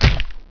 jumpland.wav